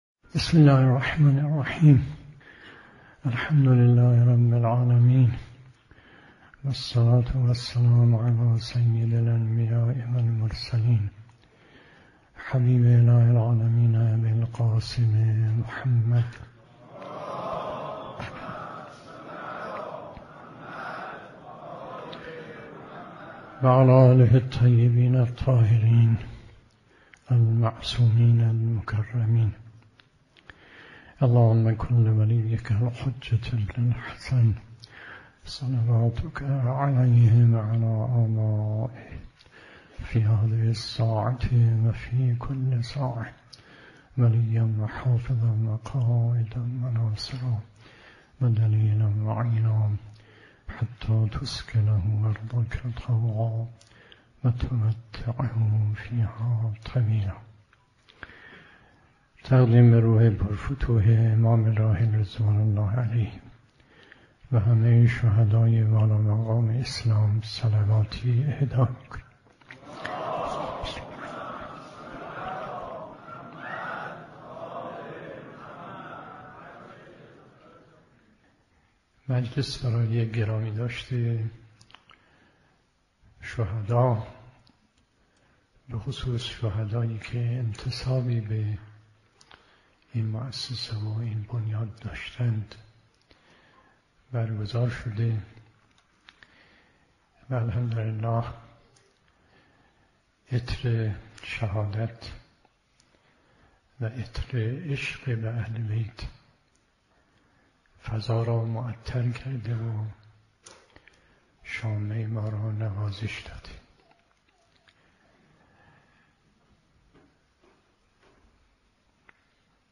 سخنرانی آیت الله مصباح یزدی درباره مراتب اشتیاق به مرگ